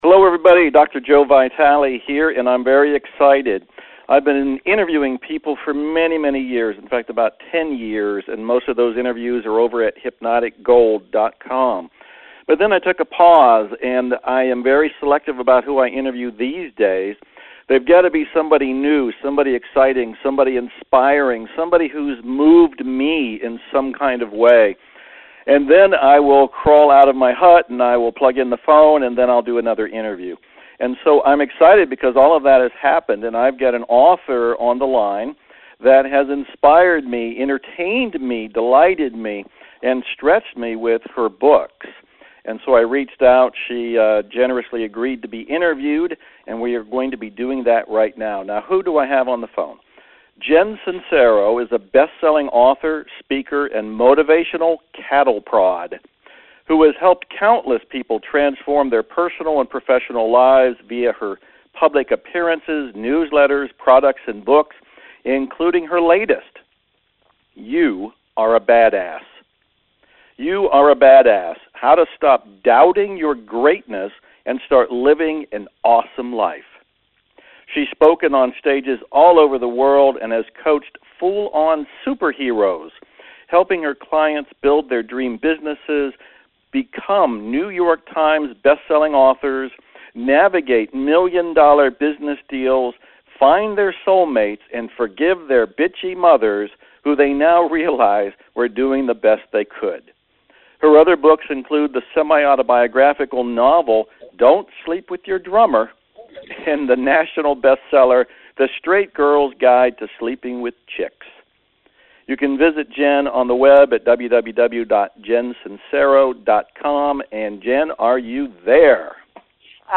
And that’s why I interviewed her.